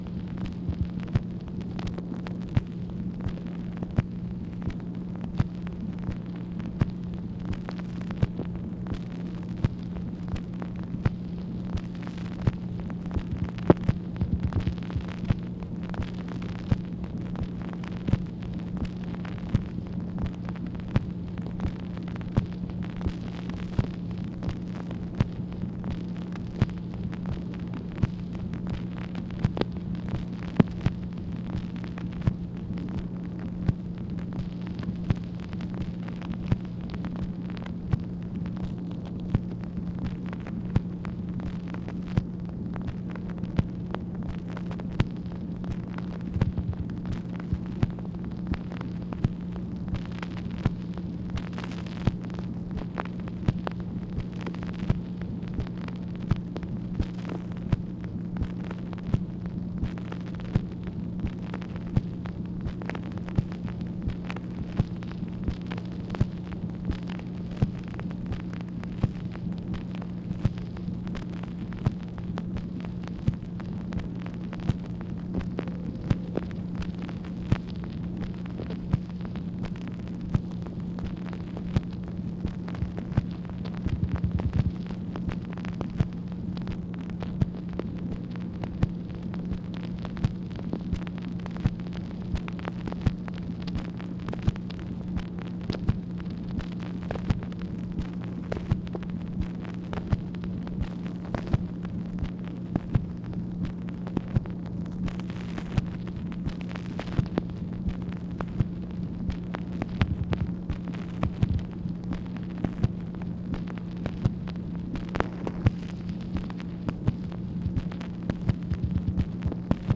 MACHINE NOISE, May 1, 1964
Secret White House Tapes | Lyndon B. Johnson Presidency